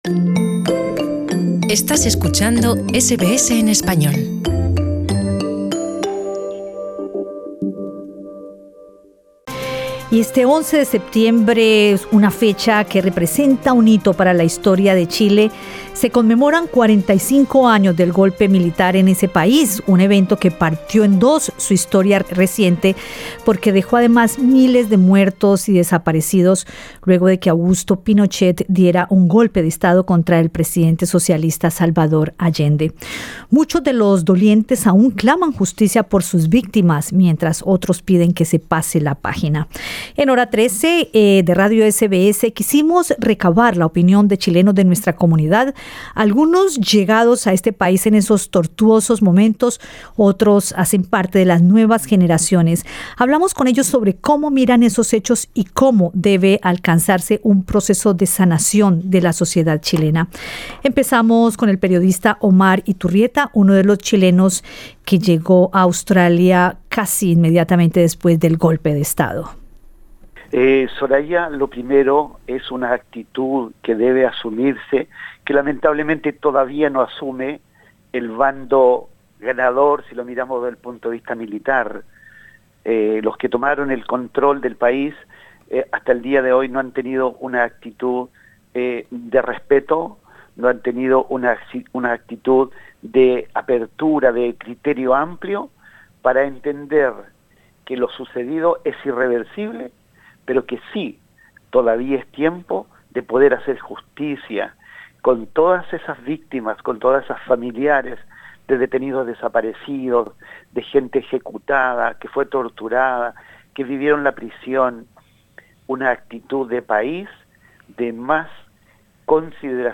Conversamos con chilenos en Australia, de distintas generaciones.